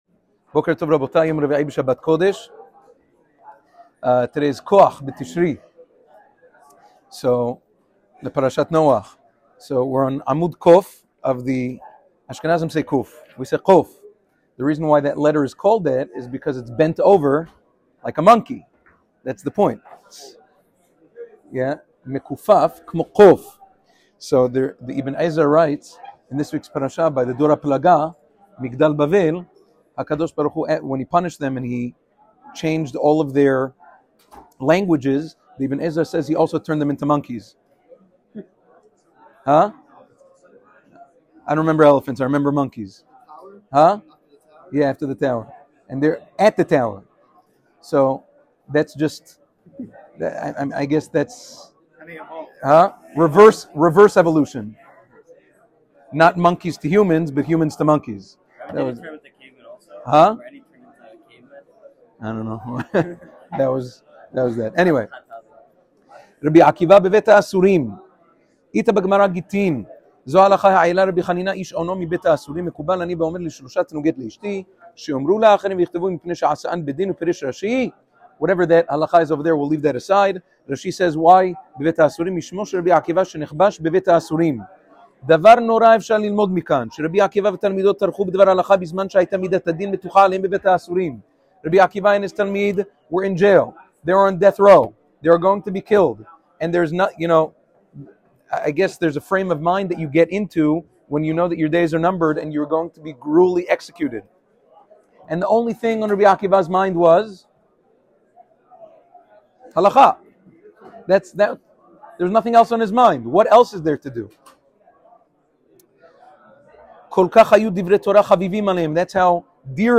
Ohr letzion volume 2 shiur #90